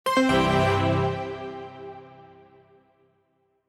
Victory SoundFX6.wav